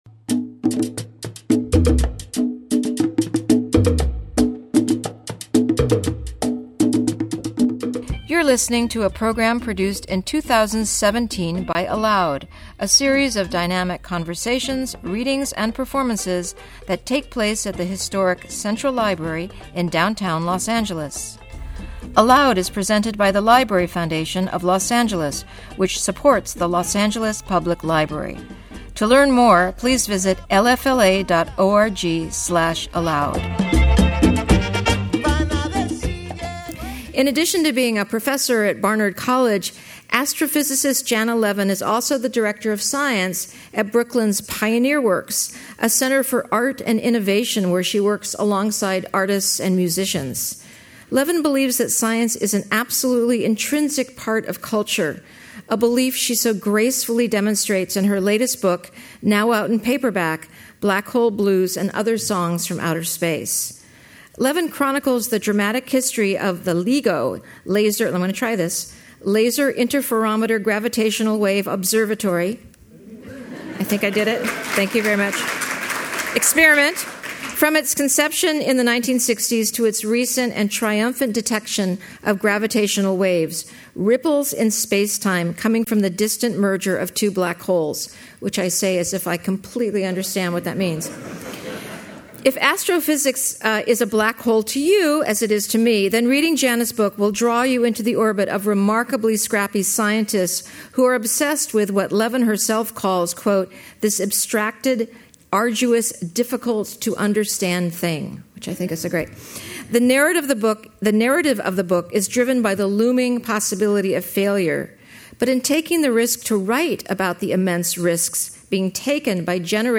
Janna Levin In Conversation With theoretical physicist Sean Carroll